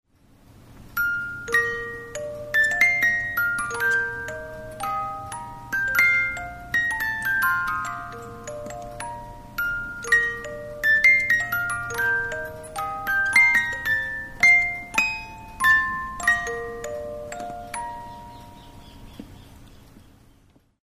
Voici une vielle qui parait bien ancienne. A vrai dire, l'origine m'en est inconnu, mais elle semble bien rudimentaire: une corde mélodique seulement, une trompette un bourdon, le tout sculpté plus ou moins dans du bois massif, roue comprise.
Ecoutez le son de ce merveilleux instrument, et vous comprendrez la supercherie.